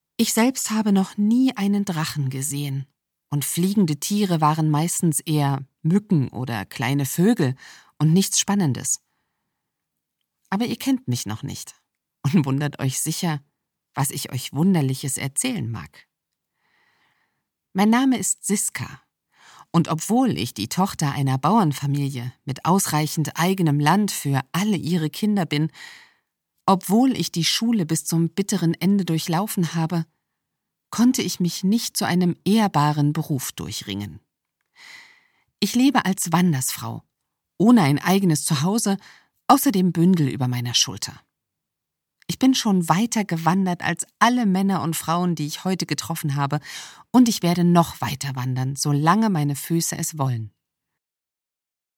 Ich habe ein warmes Timbre in mittlerer Stimmlage. Meiner Sprechweise liegt eine gewisse Fürsorglichkeit zu Grunde.
Sprechprobe: Sonstiges (Muttersprache):